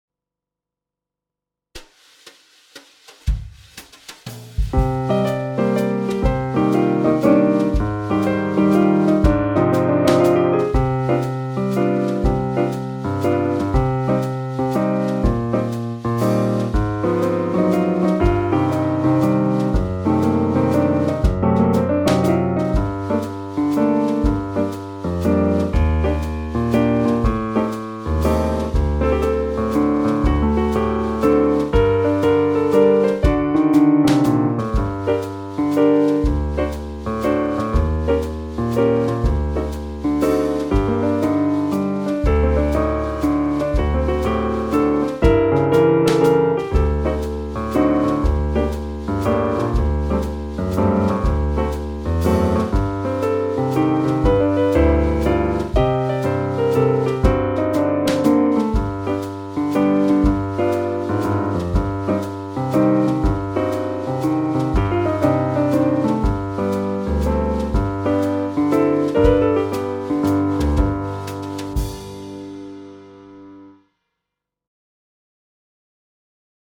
Flute solo with piano accompaniment